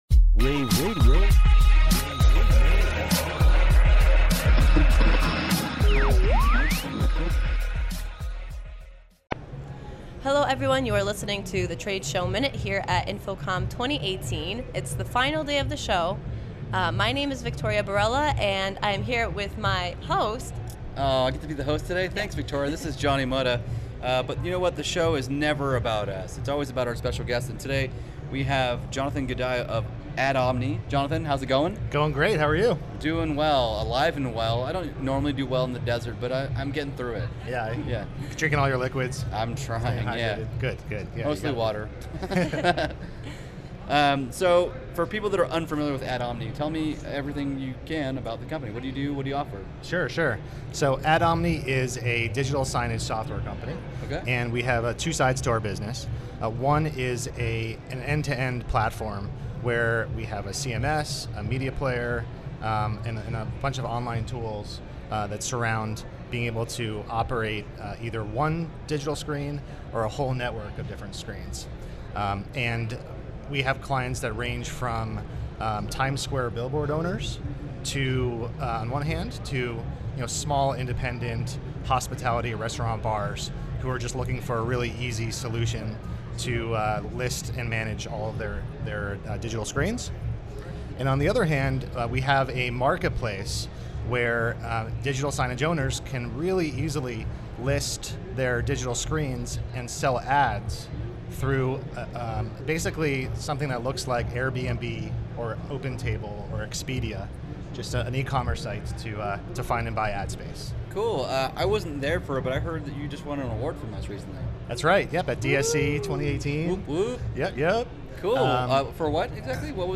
interview
at InfoComm 2018